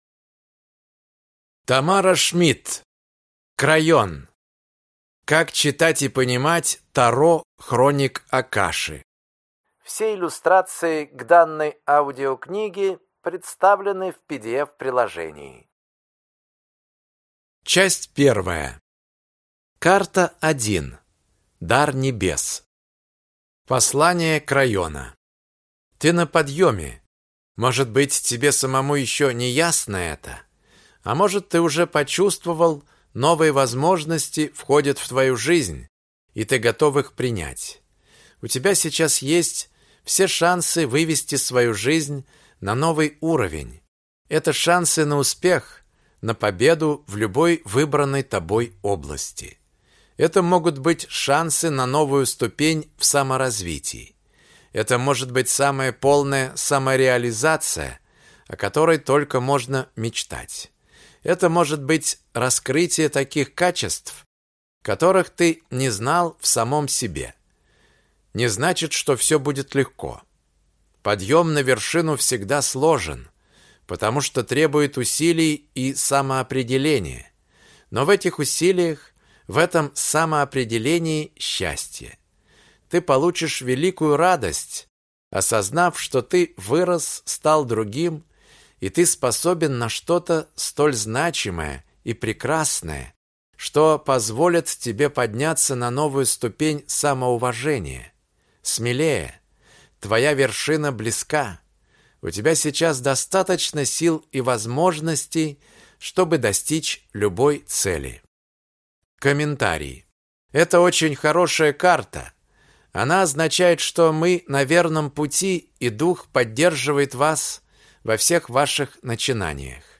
Аудиокнига Крайон. Как читать и понимать Таро Хроник Акаши | Библиотека аудиокниг